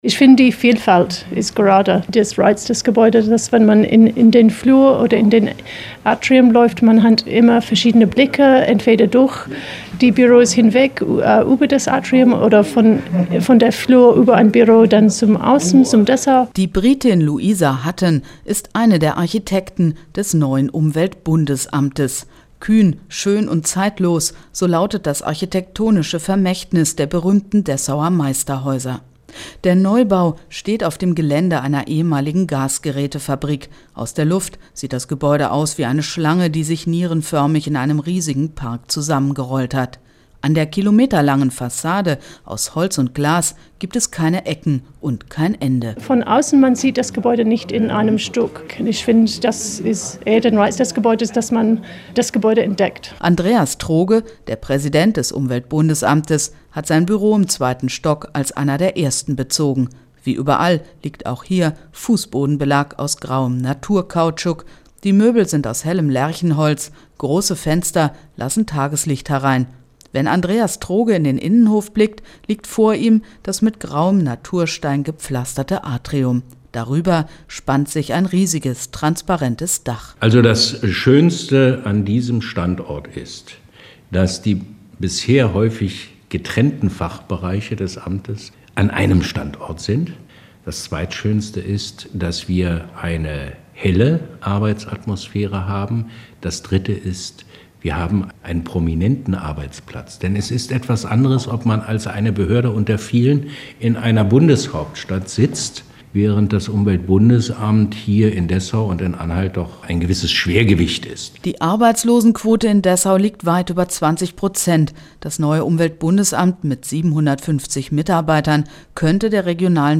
Radiobeitrag zum neuen UBA-Gebäude (Deutschlandradio 2005)